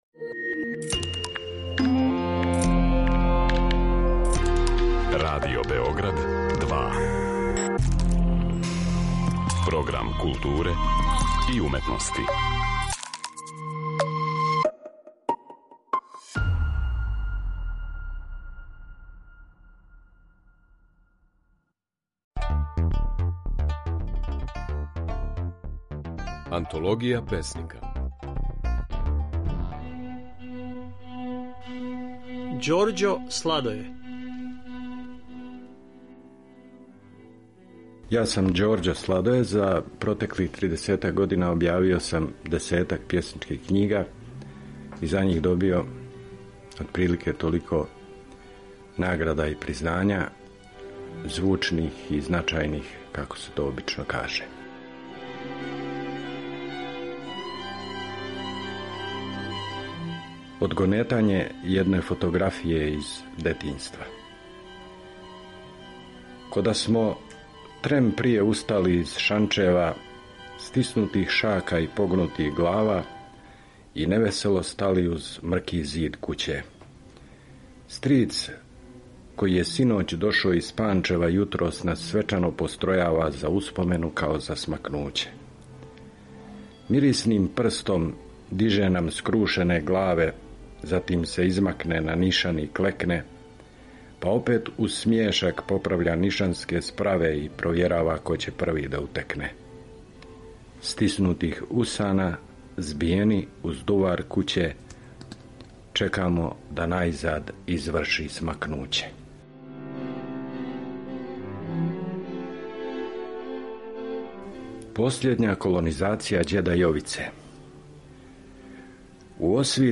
Можете чути како своје стихове говори песник Ђорђо Сладоје.
Емитујемо снимке на којима своје стихове говоре наши познати песници